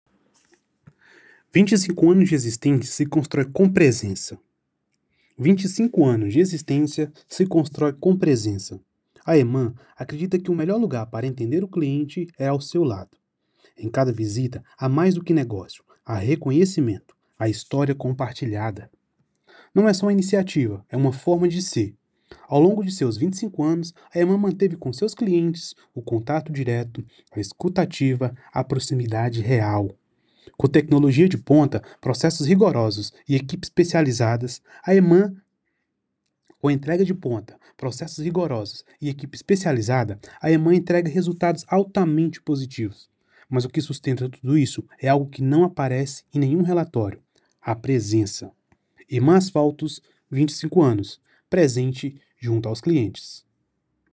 O áudio será utilizado em um mini filme institucional, então a narração precisa ser forte, emotiva e inspiracional.